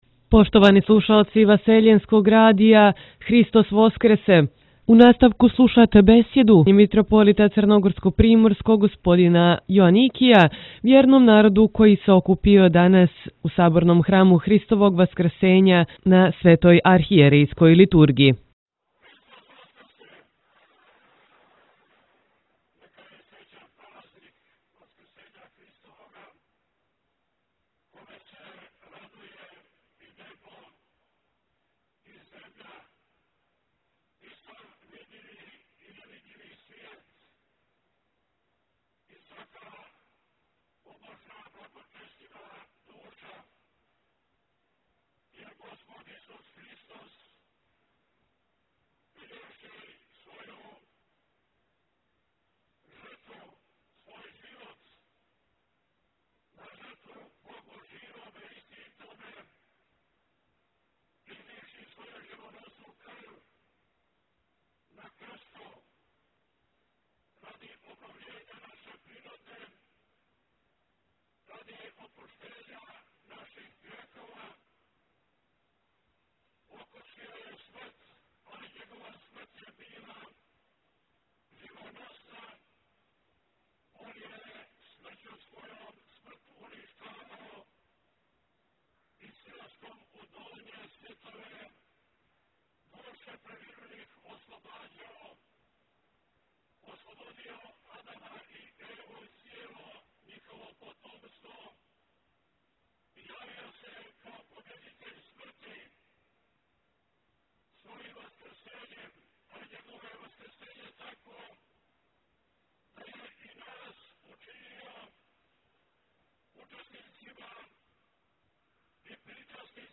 Име: 20.04.2025-Mitropolit Joanikije-besjeda hram Vaskrs; Опис: Нека се радост васкрсења Христовога и љубав излије у наша срца да живимо у братској слози и љубави Тип: audio/mpeg
Васкршњом архијерејском литургијом торжествено је прослављен Васкрс – васкрсење Господа Исуса Христа, као и слава Саборног храма Христовог Васкрсења у […]